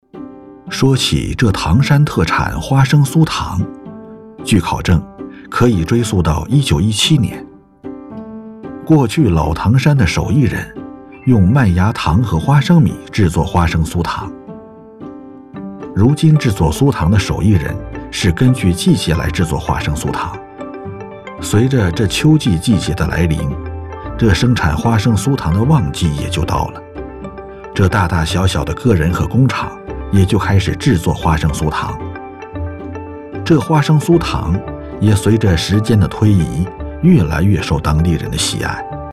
轻松自然 舌尖美食
磁性男中音，擅长不同类型的纪录片，舌尖纪录片配音、人文历史配音、讲述配音等。